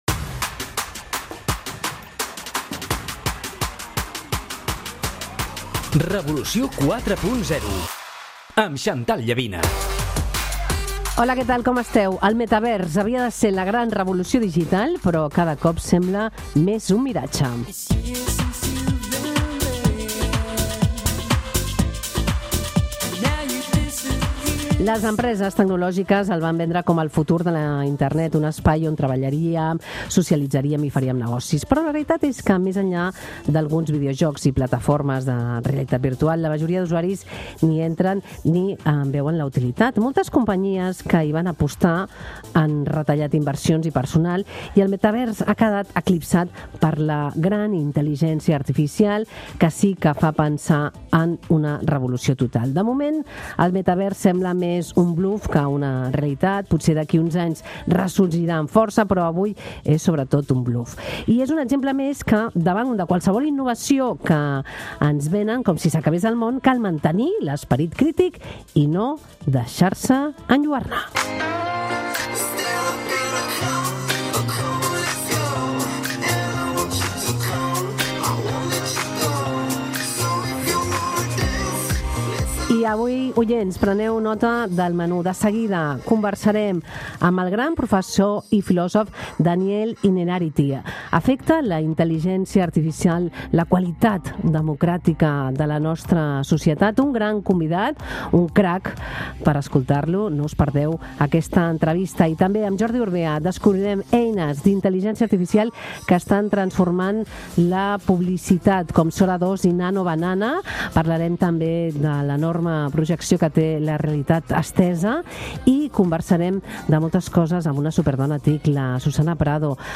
diumenge s'inicia amb una conversa amb el filòsof Daniel Innerarity, que analitza com la intel·ligència artificial pot afectar la qualitat democràtica de les nostres societats. Innerarity alerta que les tecnologies digitals estan modificant dues qüestions fonamentals: la conversa i la presa de decisions. El pensador convida a incorporar més ètica i empatia en l'ús tecnològic per preservar la llibertat i el pensament crític.